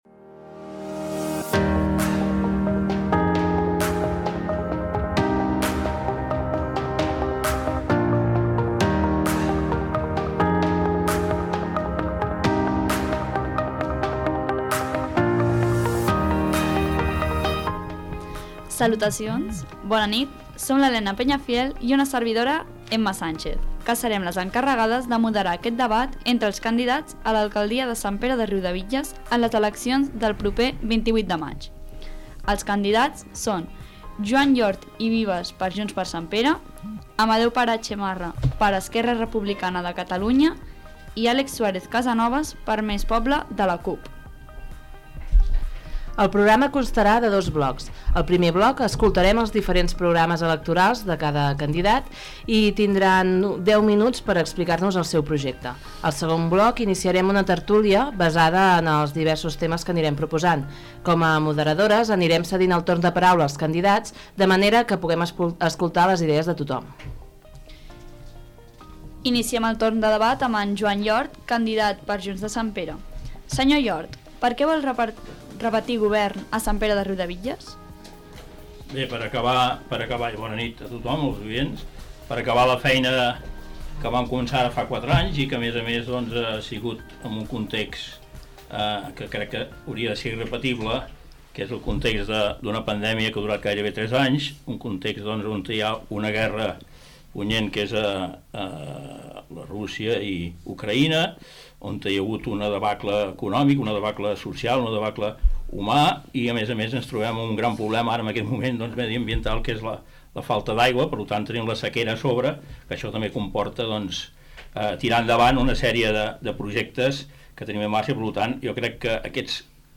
Debat electoral entre els representants polítics que es presenten a les eleccions municipals 2023. Funcionament del debat i primera intervenció d'un dels candidats
Informatiu